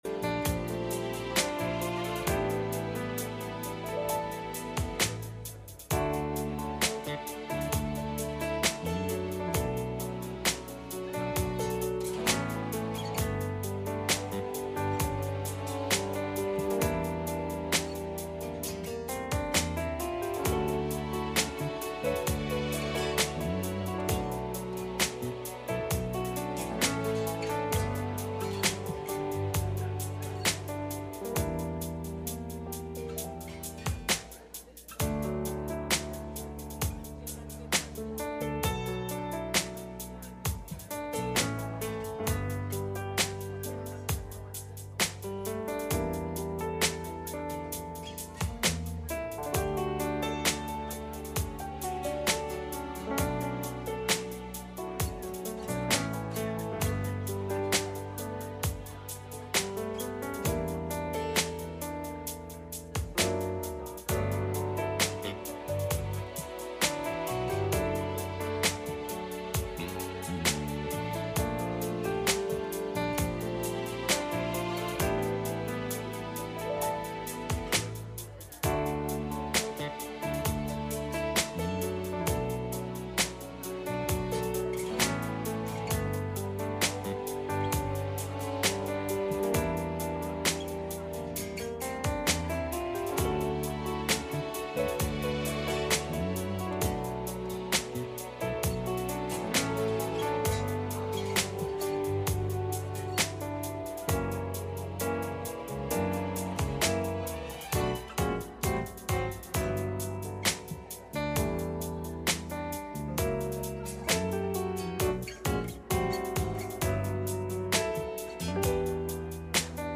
Mark 6:1-3 Service Type: Sunday Morning « The Greatest Fishing Story Ever Told